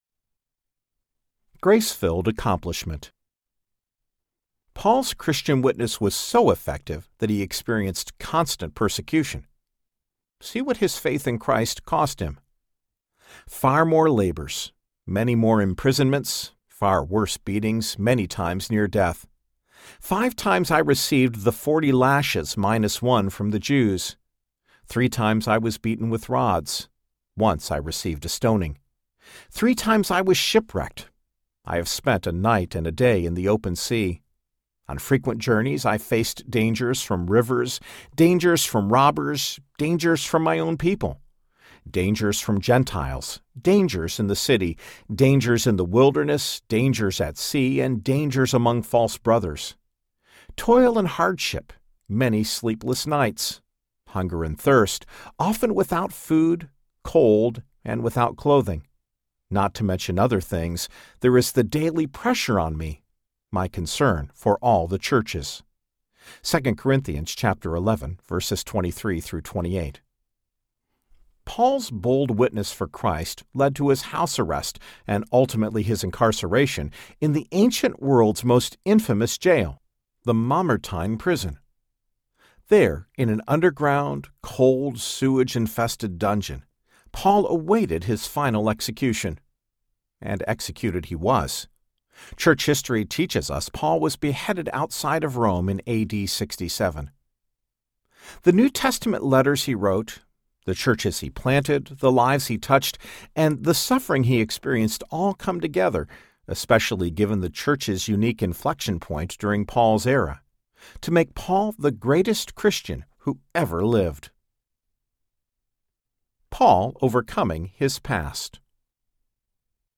Being a Christian Audiobook
Narrator
3.23 Hrs. – Unabridged